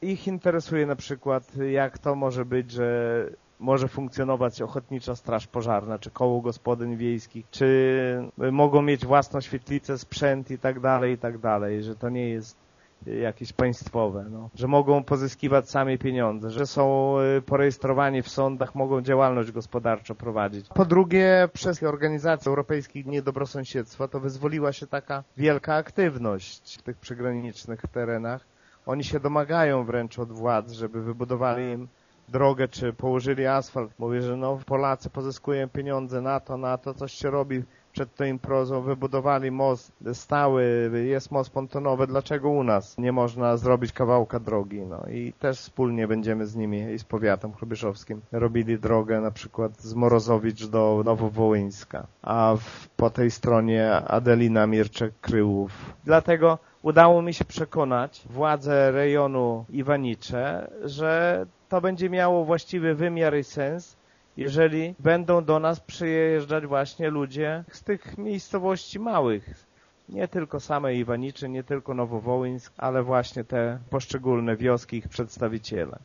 Wójt Lech Szopiński nie ukrywa, że może nie są to strategiczni partnerzy dla gminy, ale podkreśla, że są bardzo zainteresowani tym co dzieje się na polskich wsiach: